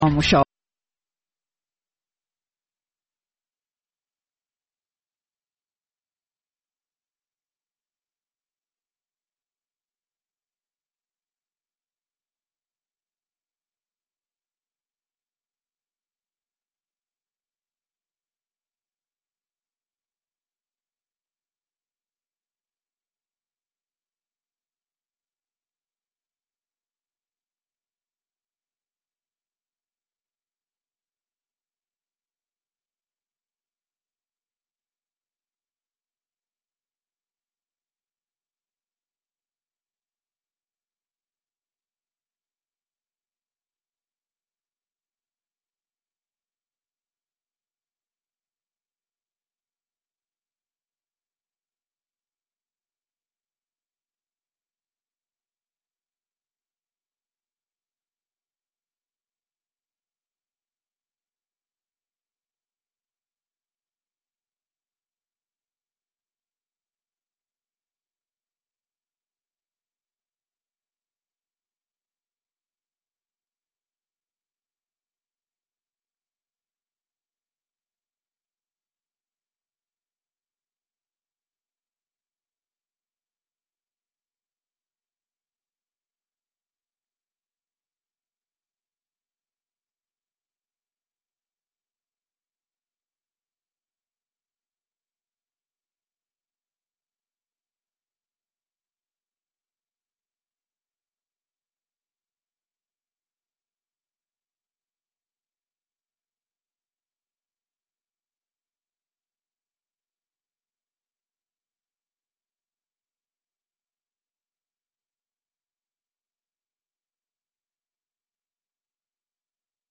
ახალი ამბები (რადიო თავისუფლება) + Music Mix ("ამერიკის ხმა")